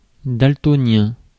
Prononciation
Prononciation France: IPA: [dal.tɔ.njɛ̃] Le mot recherché trouvé avec ces langues de source: français Traduction 1.